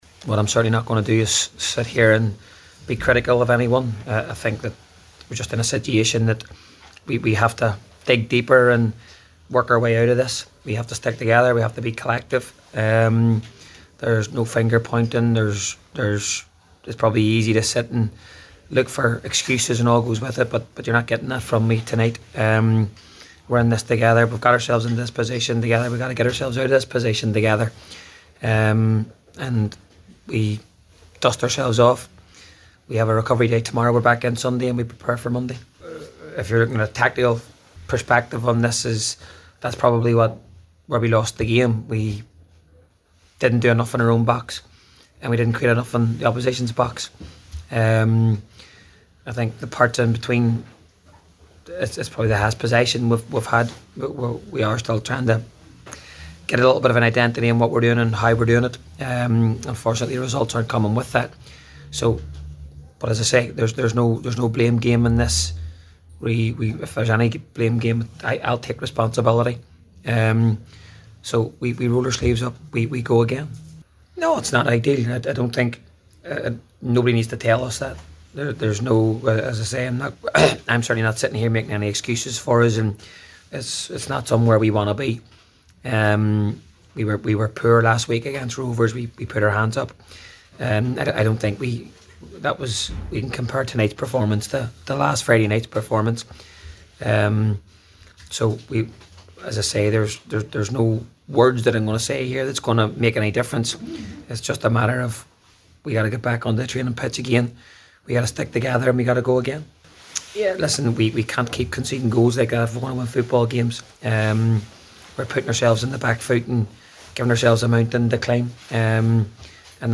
spoke to the media after tonight’s defeat